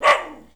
Index of /90_sSampleCDs/E-MU Producer Series Vol. 3 – Hollywood Sound Effects/Human & Animal/Dogs
NASTY BARK-L.wav